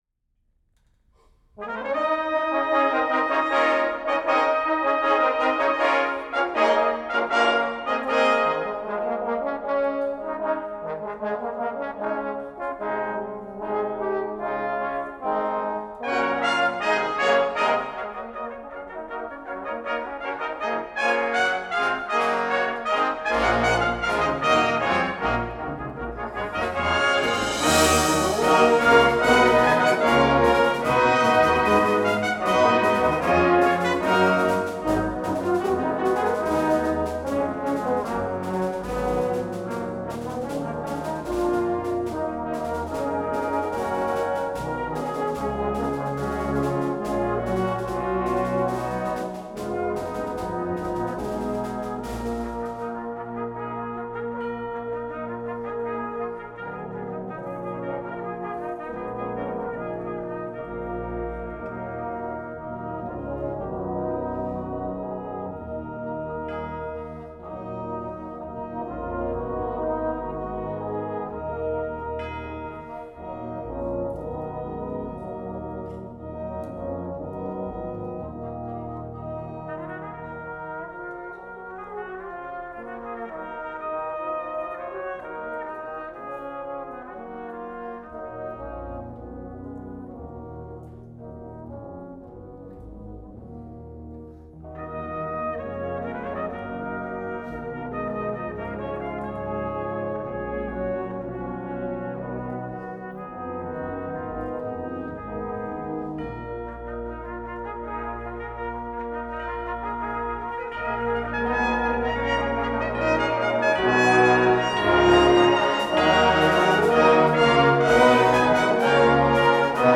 Performing British Brass Band Music in Central Texas